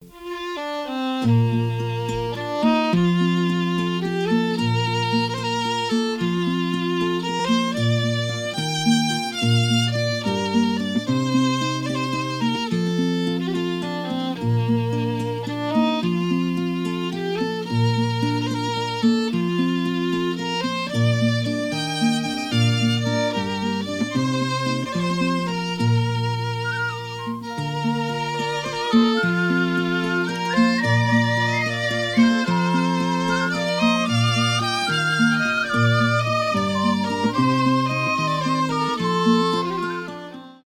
инструментальные , без слов
рок